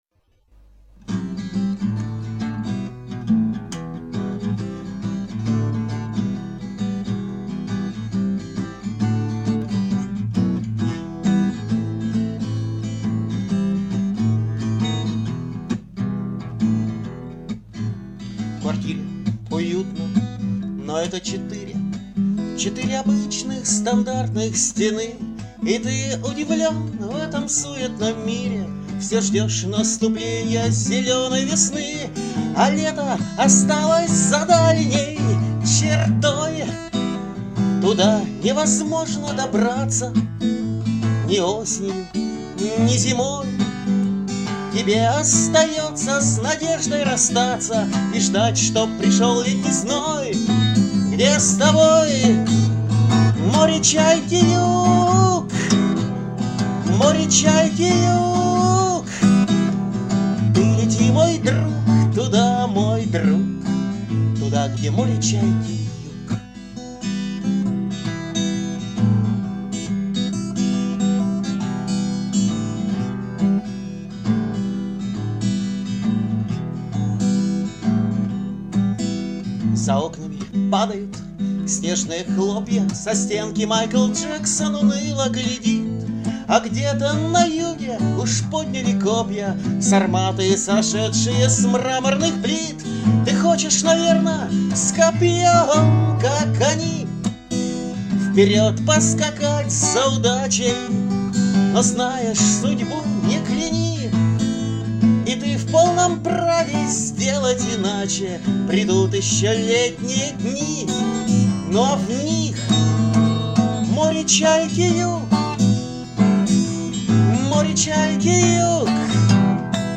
Бардрок (4123)